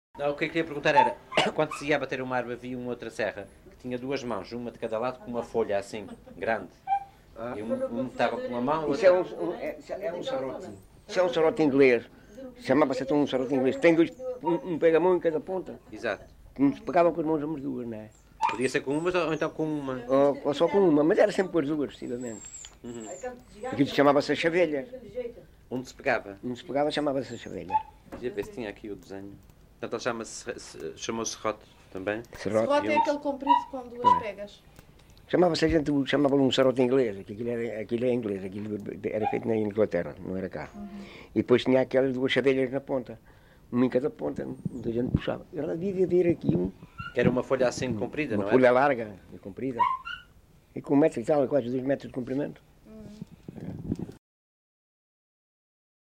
LocalidadeMoita do Martinho (Batalha, Leiria)